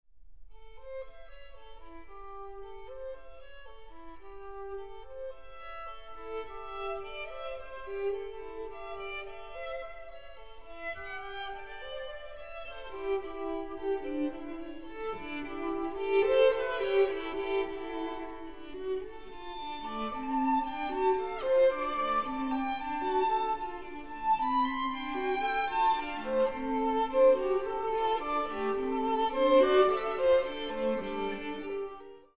Super Audio CD